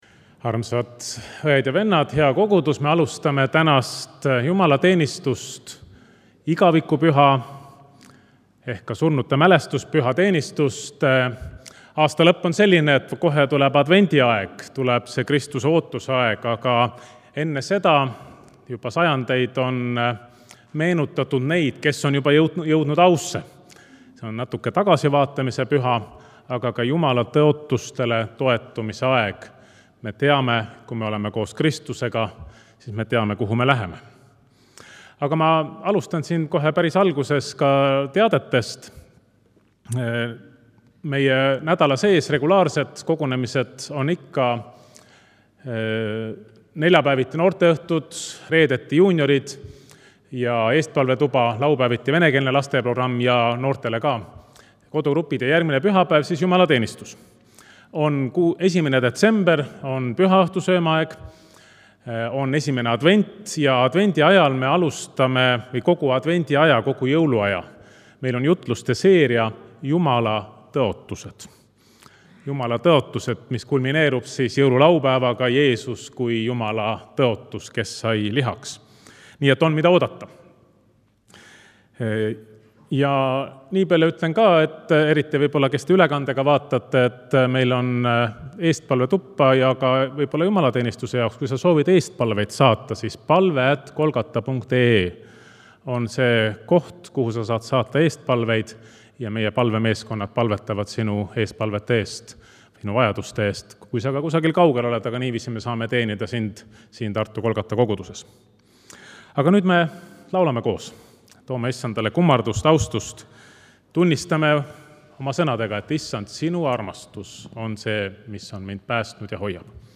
Pühakirja lugemine
Igavikku läinute mälestamine
Jutlus